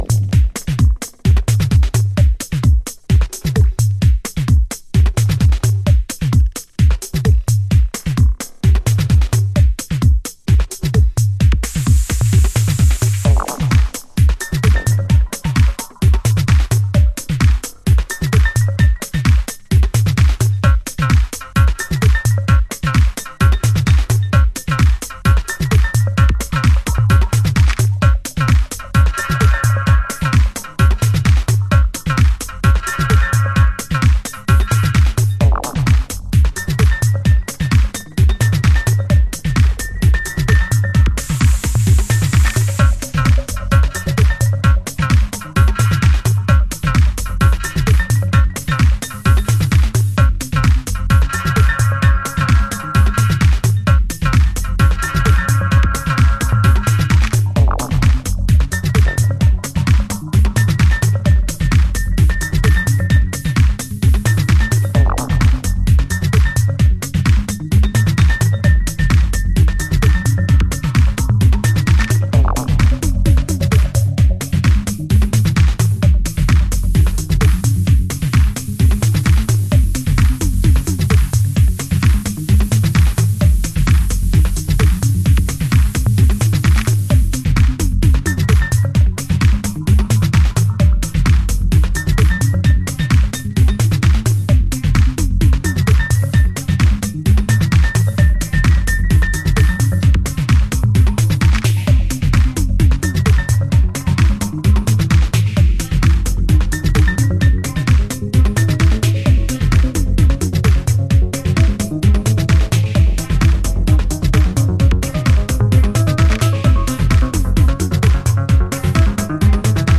House / Techno
両者の持ち味が活かされた、シンプル&ハードなテクノトラックス。